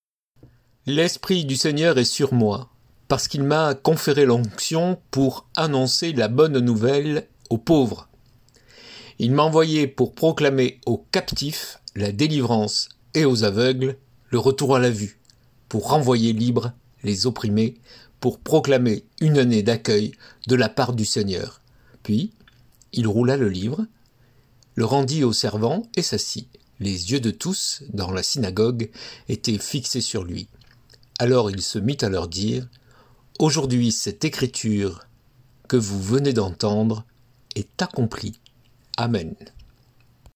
salutation